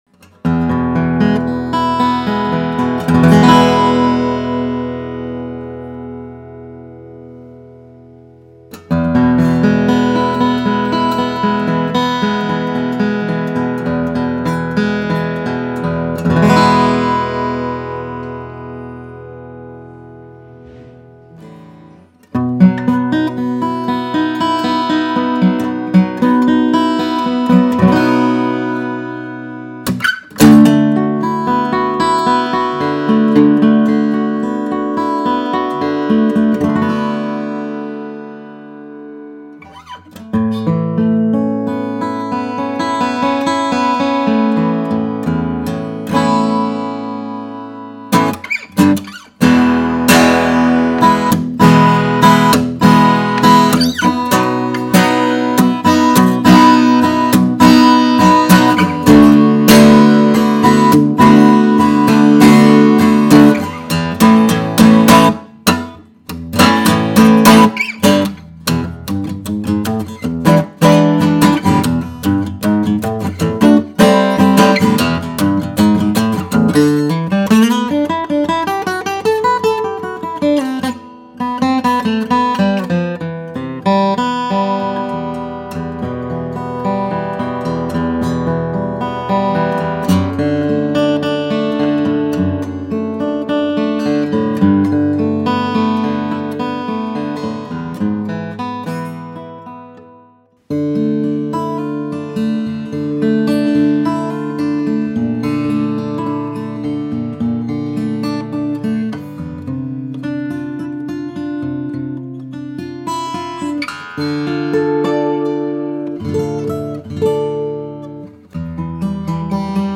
Brilliant Santa Cruz Vintage Southerner built with premium Honduran Mahogany and Adirondack Red Spruce.
She offers that dry, punchy midrange and warm bottom-end bark you’d expect from a vintage slope-shoulder dreadnought — but with the refinement, responsiveness, and articulation that only Santa Cruz can deliver.